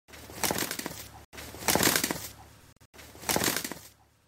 Tiếng Gà Chọi Đá nhau, Tung đòn, Lên đòn…
Thể loại: Tiếng vật nuôi
Description: Tiếng gà chọi đá nhau, tung đòn, lên đòn vang lên mạnh mẽ, phản ánh từng chuyển động quyết liệt trên chiến trường nhỏ.
tieng-ga-choi-da-nhau-tung-don-len-don-www_tiengdong_com.mp3